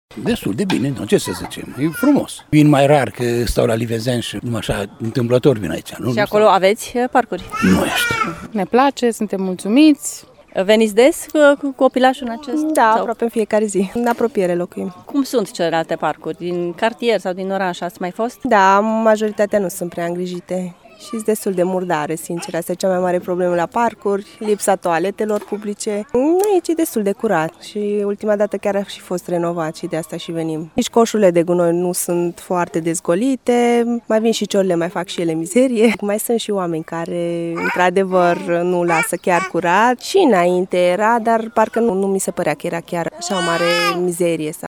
Majoritatea parcurilor din oraș se află însă într-o stare deplorabilă, spun unii părinți, nemulțumiți și de lipsa toaletelor publice din parcuri dar și de mizeria generală din oraș: